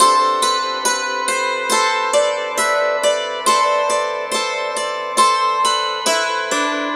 Dulcimer04_69_G.wav